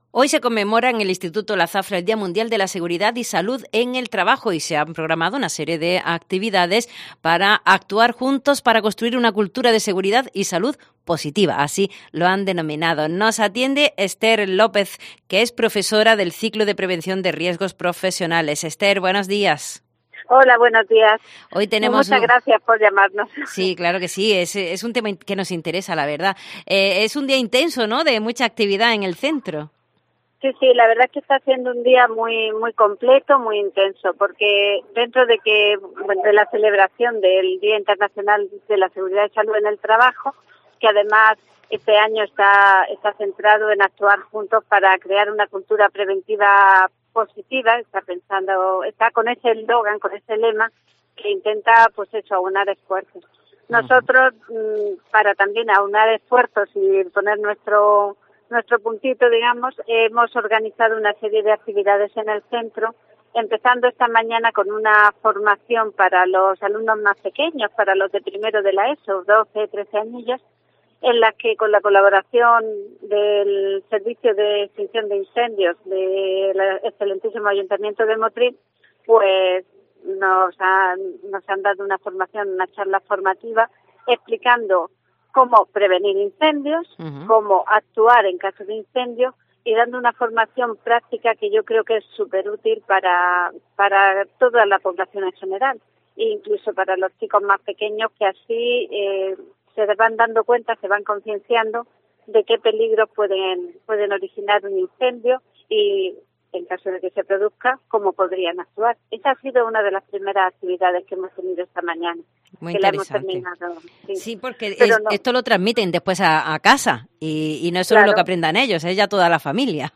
También se ha celebrado un simulacro de incendio en el centro que ha tenido bastante éxito.